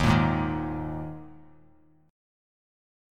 D#sus4 chord